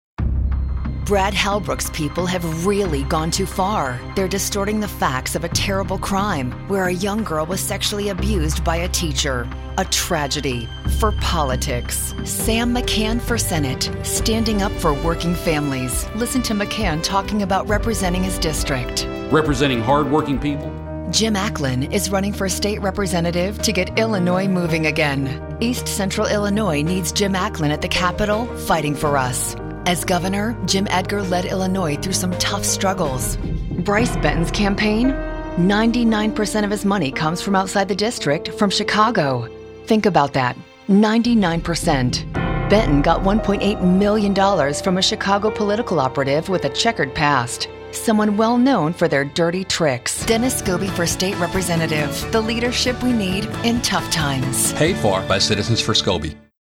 Political demo
English - USA and Canada